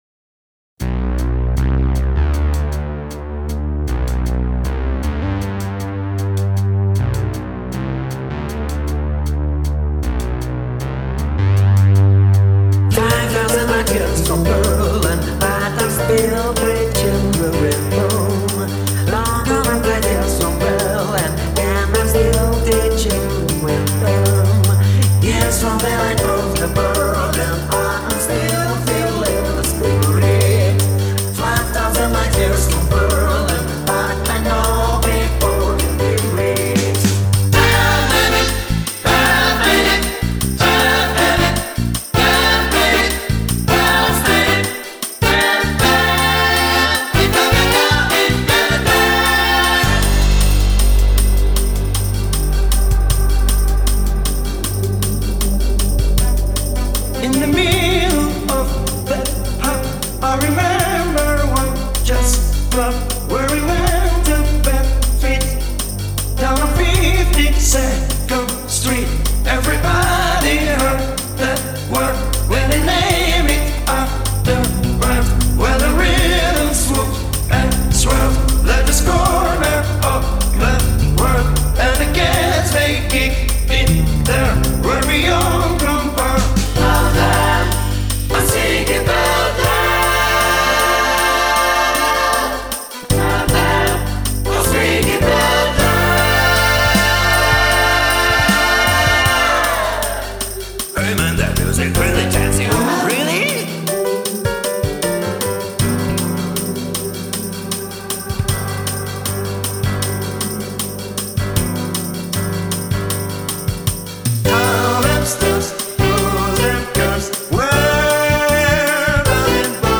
męski kwartet wokalny